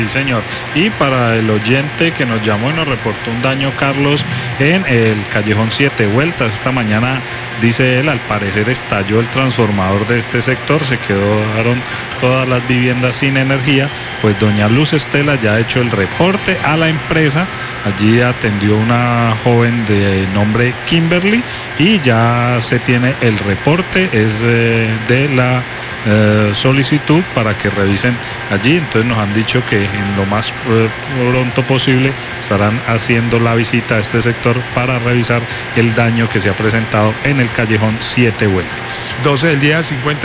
Radio
reporte